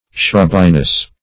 Shrubbiness \Shrub"bi*ness\, n. Quality of being shrubby.
shrubbiness.mp3